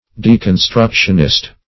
deconstructionist \de`con*struc"tion*ist\
(d[-e]`k[u^]n*str[u^]k"sh[u^]n*[i^]st), adj.